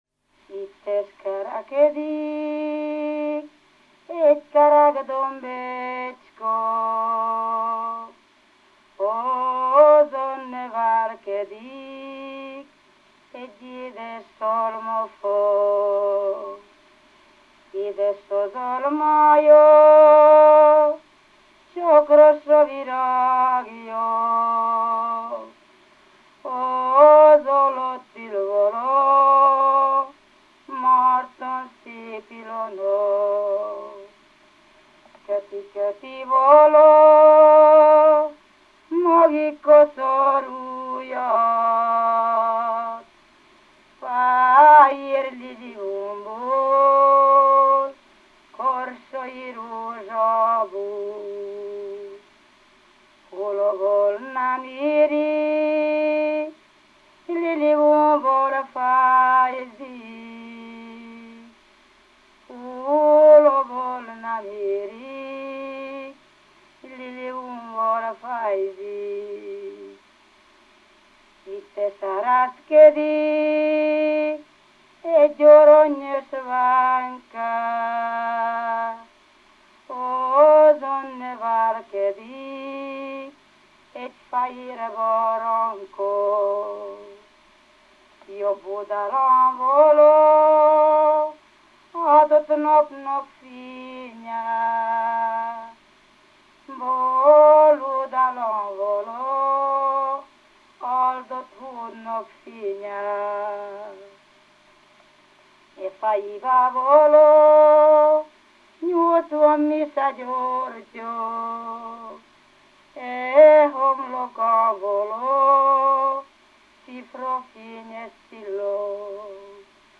ének
ballada
Klézse
Moldva (Moldva és Bukovina)